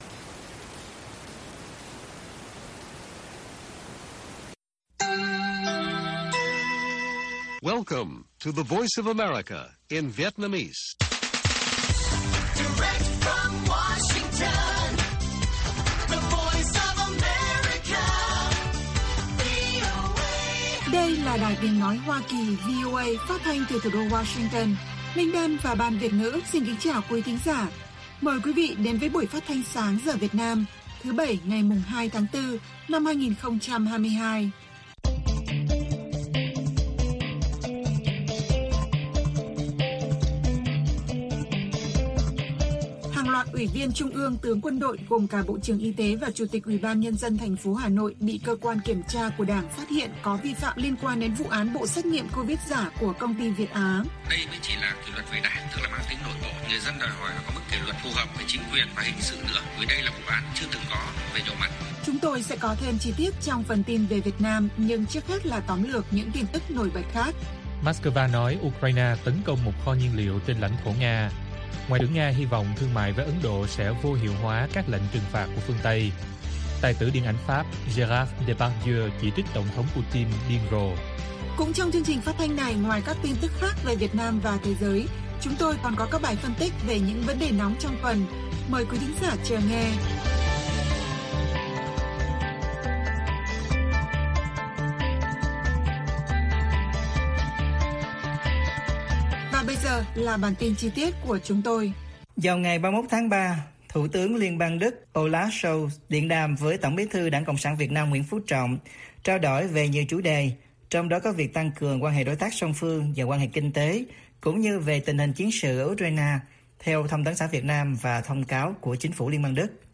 Moscow nói Ukraine tấn công một kho nhiên liệu bên trong nước Nga - Bản tin VOA